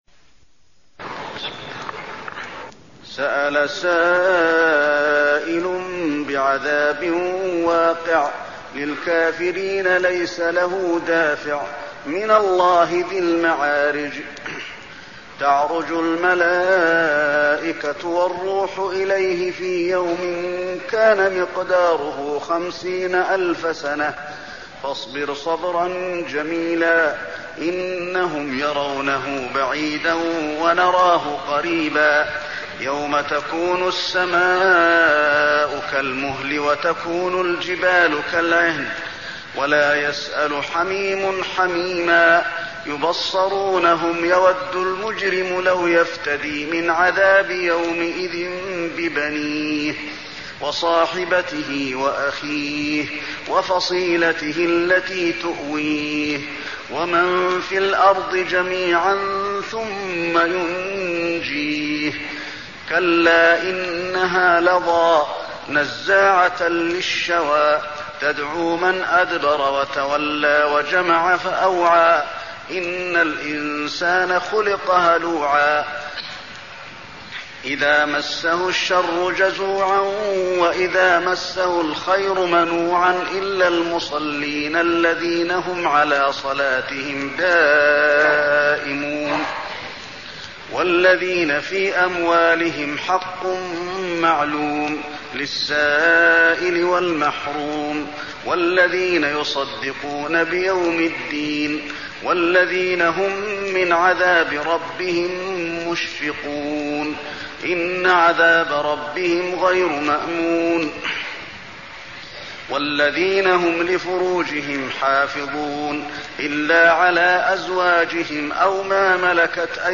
المكان: المسجد النبوي المعارج